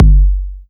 KICK174.wav